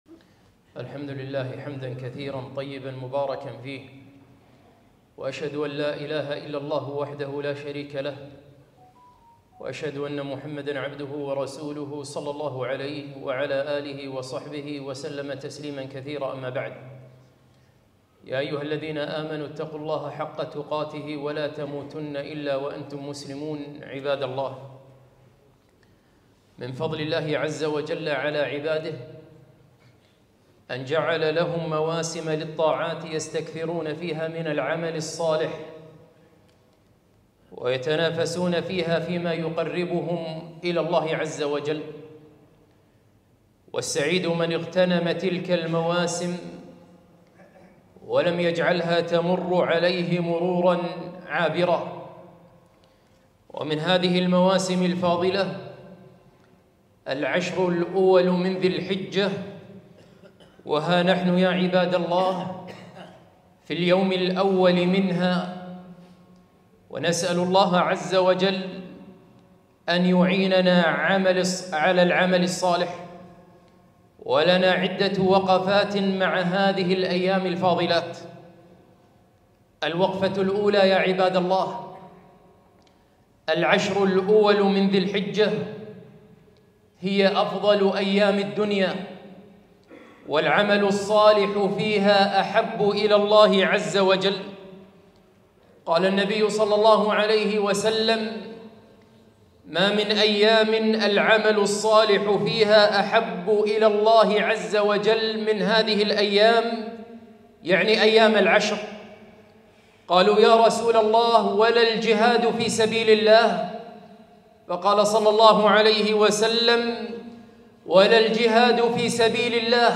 خطبة - اجتهدوا في عشر ذي الحجة